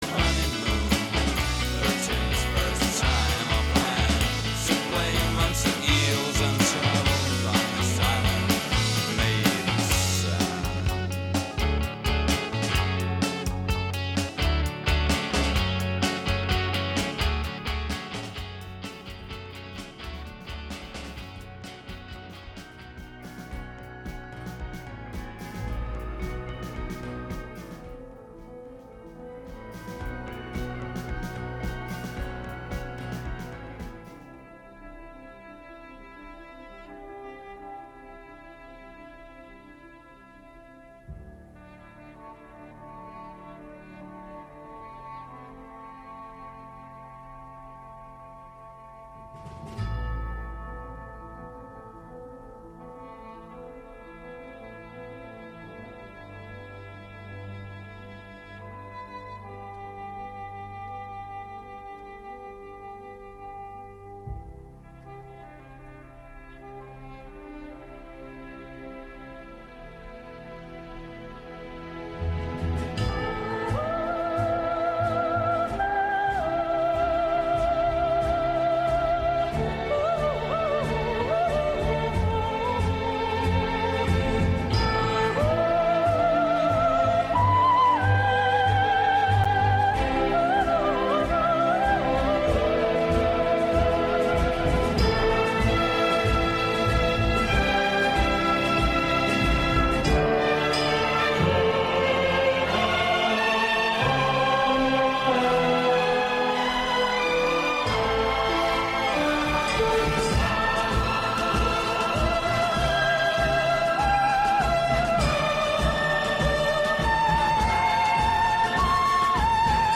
spins a variety mix of music from artists you know and love